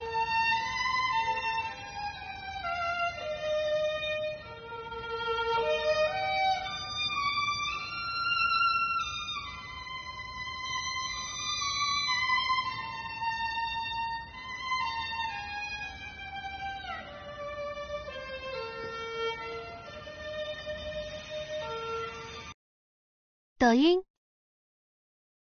一名门卫大爷
独自用小提琴演奏乐曲的场景
一阵悠扬美妙的曲子飘扬在风中